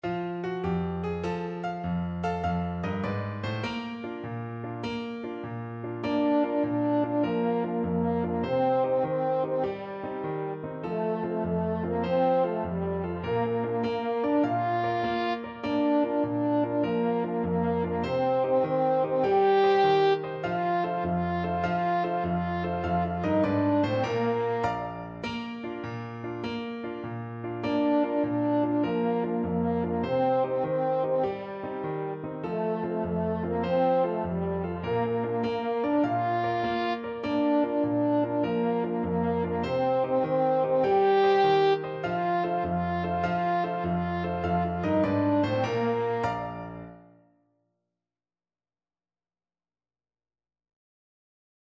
French Horn
Bb major (Sounding Pitch) F major (French Horn in F) (View more Bb major Music for French Horn )
Allegro .=c.100 (View more music marked Allegro)
6/8 (View more 6/8 Music)
Traditional (View more Traditional French Horn Music)